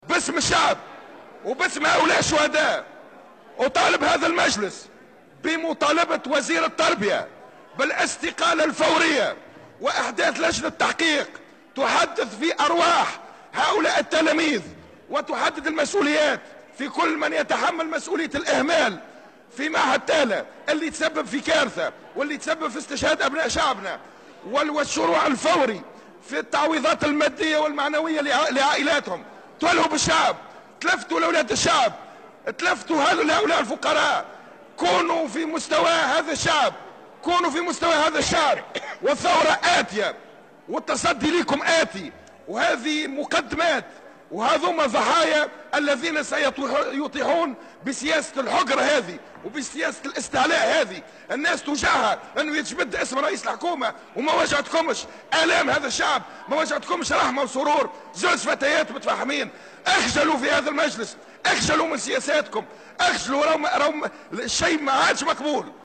Le député du Front Populaire à l'assemblée, Aymen Aloui a appelé l'Assemblée des représentants du peuple (ARP), lors de la plénière de ce mercredi 7 février 2018, à réclamer la démission du ministre de l'Education Hatem Ben Salem, suite au décès de deux adolescentes lors de l'incendie survenu dans le foyer pour jeunes filles au collège 25 juillet à Thala dans le gouvernorat de Kasserine.